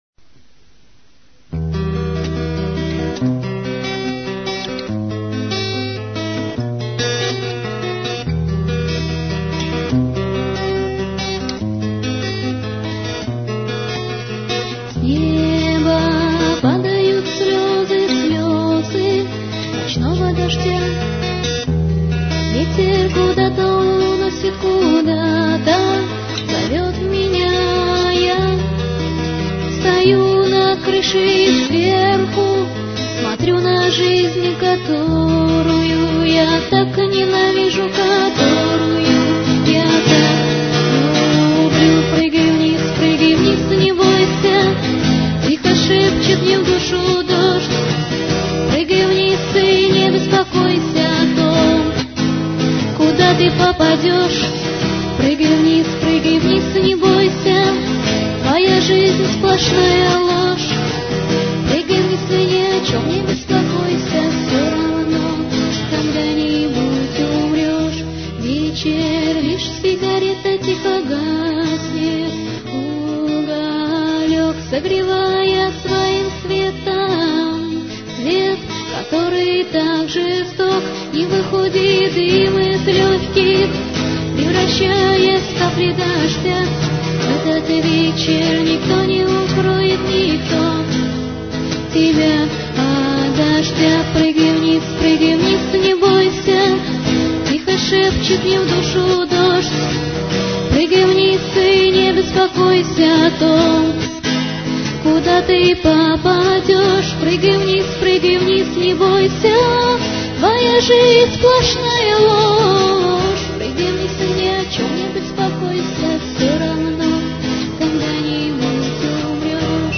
А песеня прикольноя, давно не слышал гитару